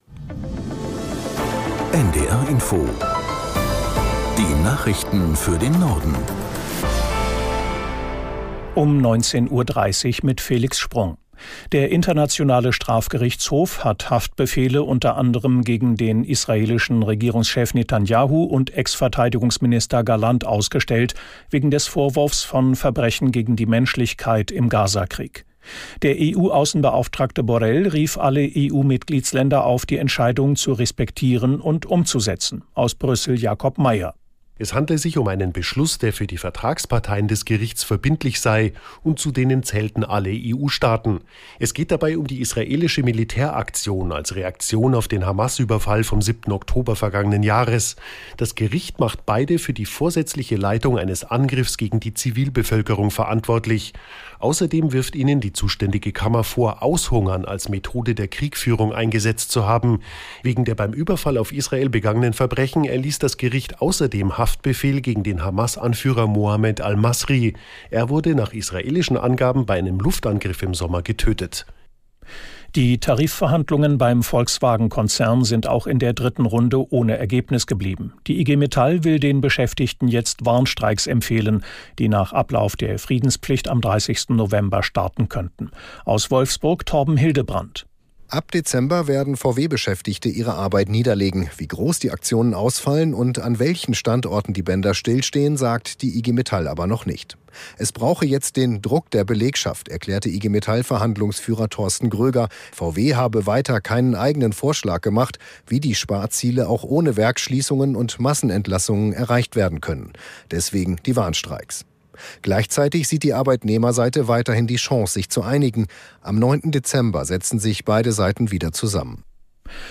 Nachrichten für den Norden. Alle 30 Minuten die aktuellen Meldungen aus der NDR Info Nachrichtenredaktion. Politik, Wirtschaft, Sport. 24 Stunden am Tag - 365 Tage im Jahr.